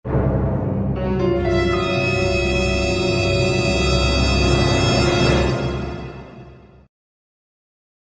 Hiệu ứng âm thanh SUSPENSE #1 mp3 - Tải hiệu ứng âm thanh để edit video